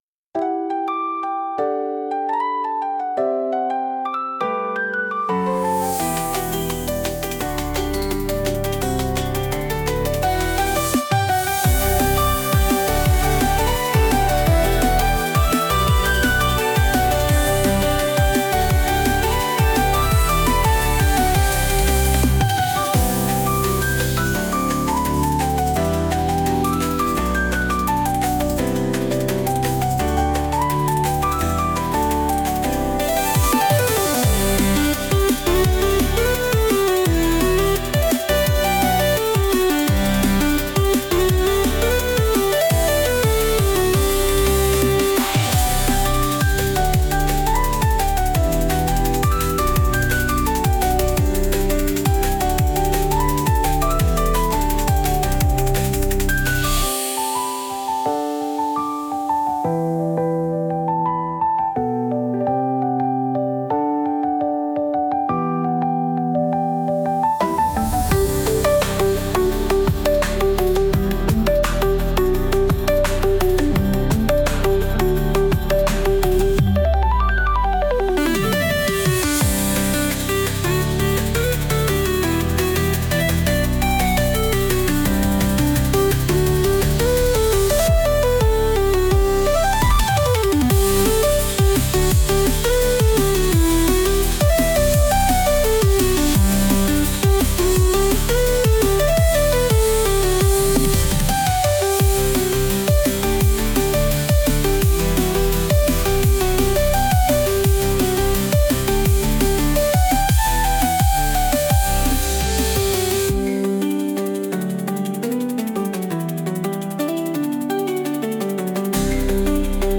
大切なことを忘れないためのBGM